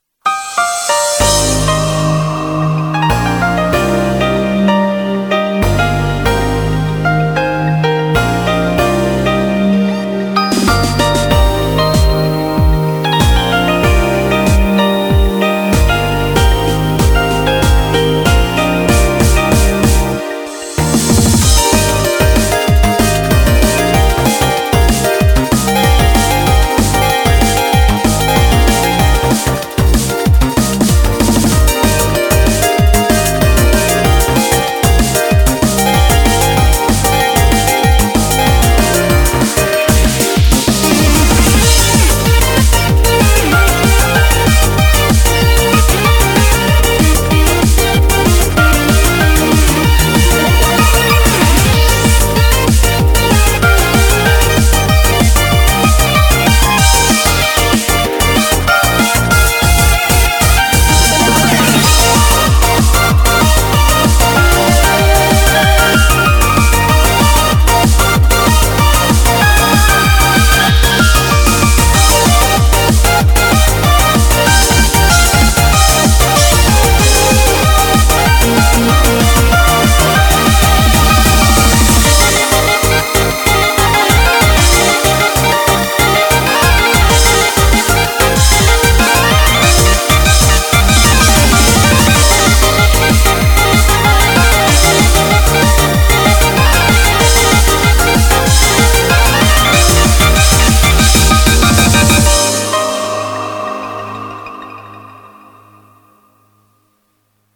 BPM190
Comments[TEK-TRANCE]